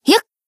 BA_V_Kayoko_Newyear_Battle_Shout_2.ogg